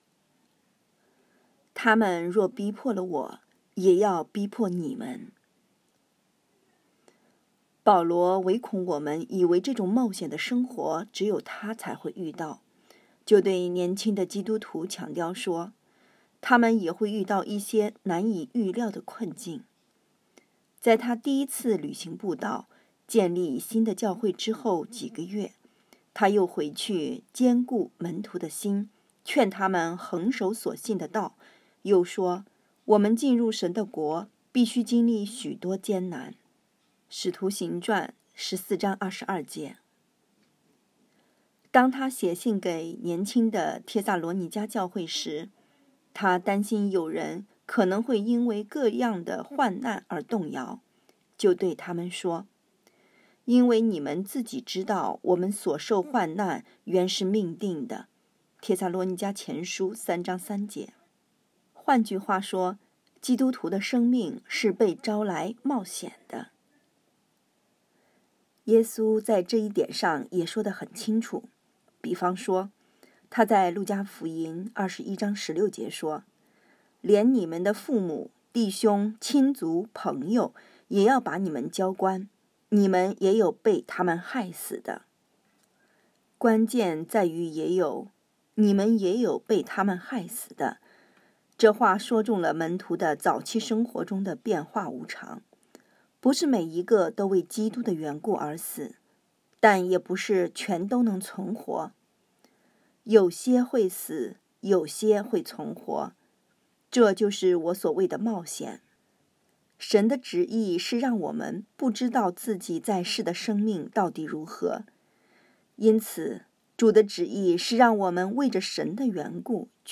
2024年2月16日 “伴你读书”，正在为您朗读：《活出热情》 欢迎点击下方音频聆听朗读内容 https